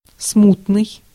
Ääntäminen
IPA: /ɔp.skyʁ/